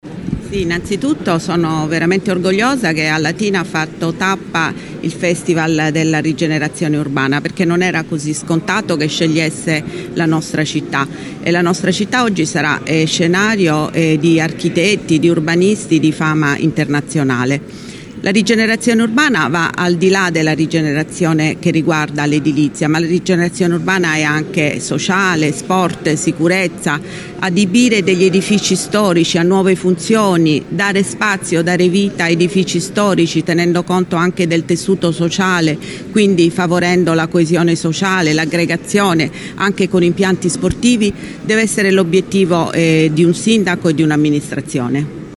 La tappa di Latina che andrà avanti per tutta la giornata è iniziata alle 10 al Museo Cambellotti.
A fare gli onori di casa la Sindaca di Latina Matilde Celentano